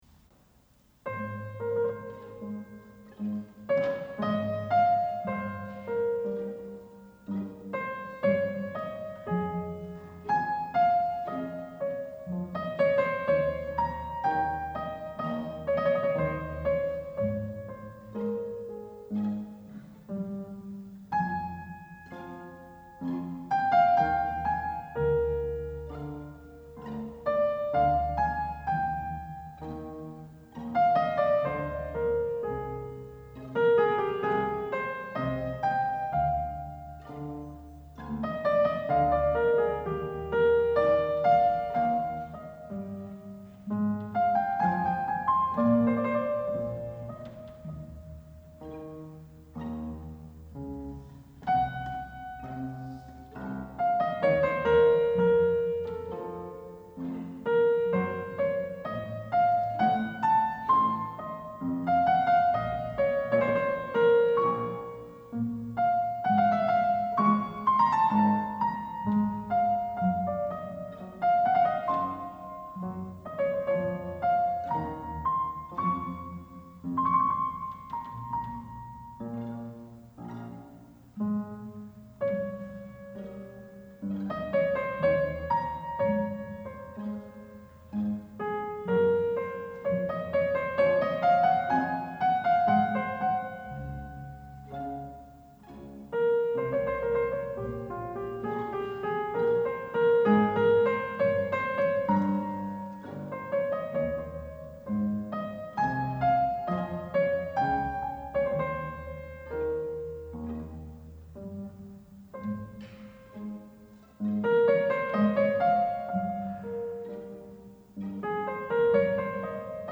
Enregistrement live au Victoria Hall
Concerto pour clavier et orchestre
BWV 1056 en fa mineur